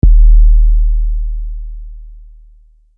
808 knock.wav